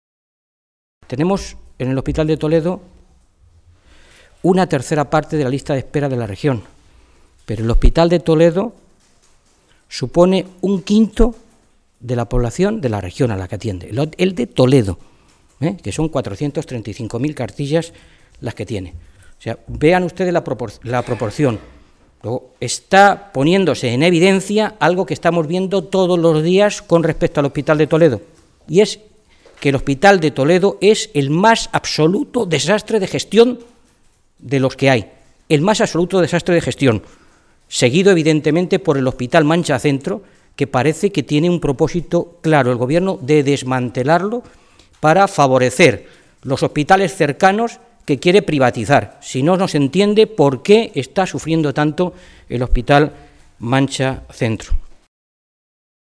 Fernando Mora, portavoz de Sanidad del Grupo Socialista
Cortes de audio de la rueda de prensa